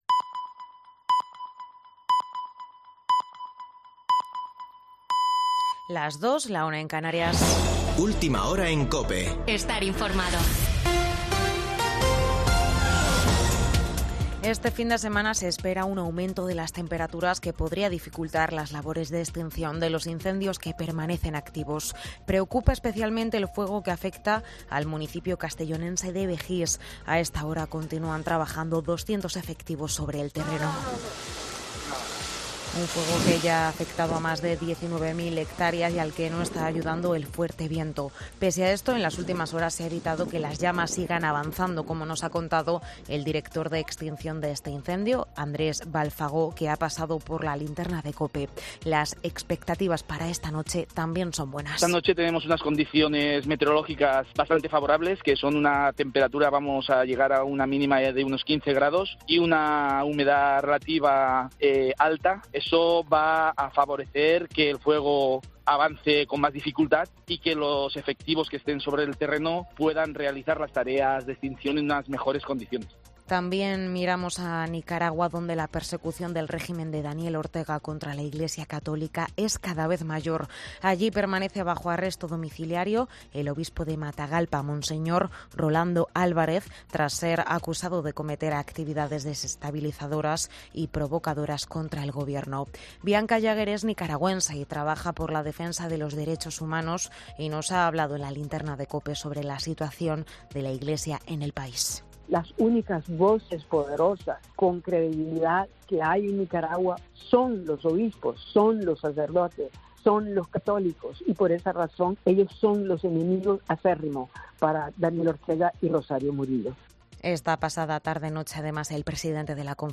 Boletín de noticias de COPE del 20 de agosto de 2022 a las 02.00 horas